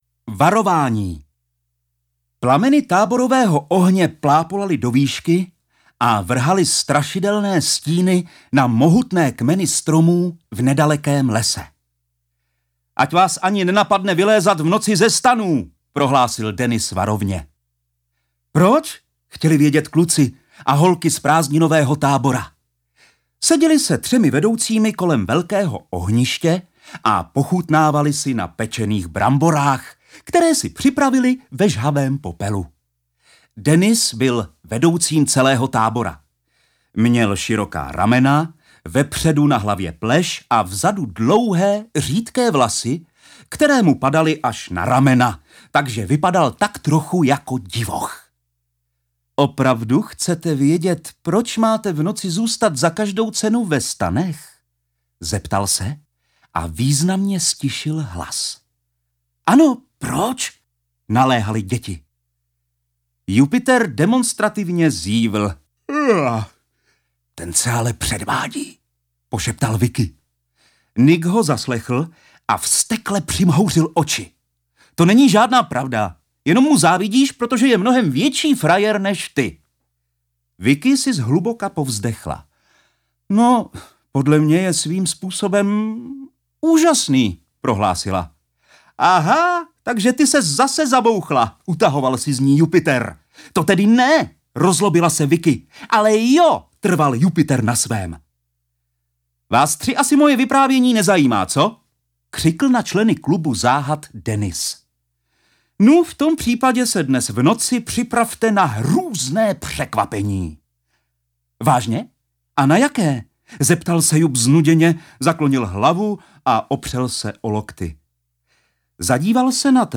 Audio knihaKlub záhad - Tajemství stříbrných vlků
Ukázka z knihy